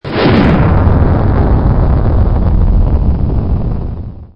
Download Rocket Launch sound effect for free.
Rocket Launch